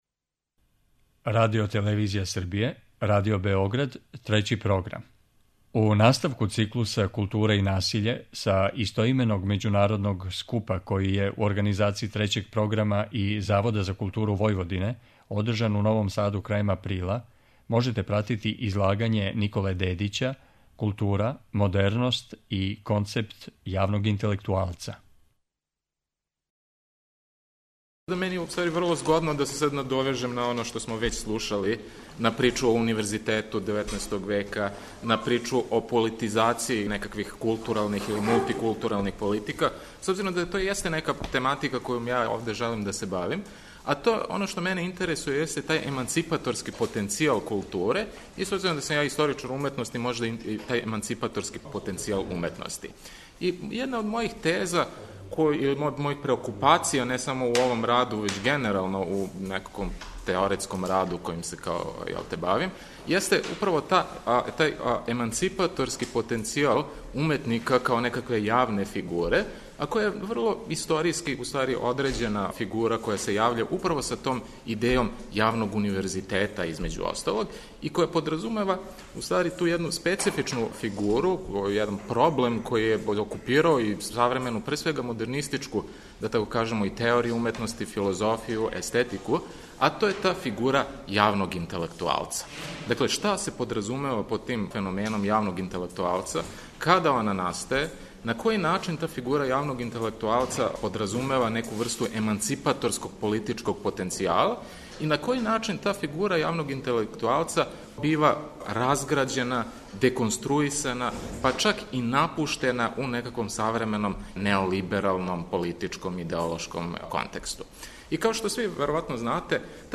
У циклусу КУЛТУРА И НАСИЉЕ, који емитујемо средом, са истоименог научног скупа који су, у Новом Саду крајем априла, организовали Трећи програм и Завод за културу Војводине, емитујемо прилоге са овога скупа и разговоре о излагањима.